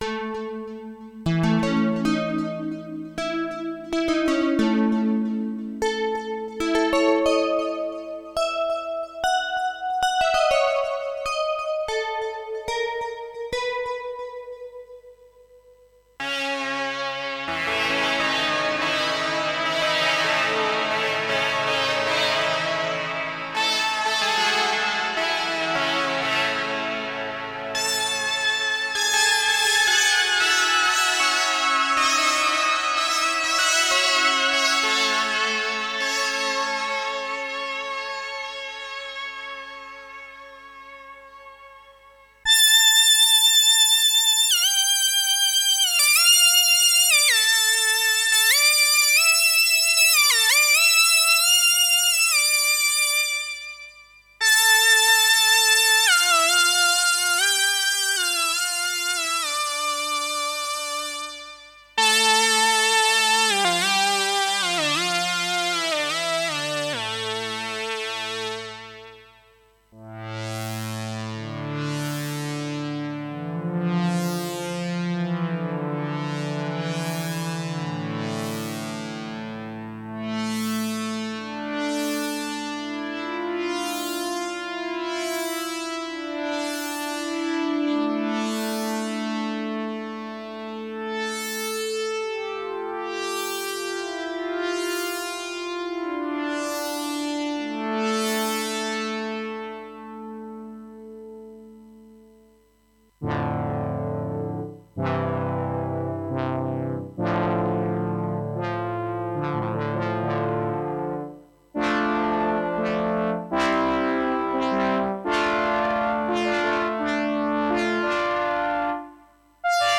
PACK SOUNDS | TI2 SYNTHESIZER❤
DEMO-Optical-Chronoligic-01.mp3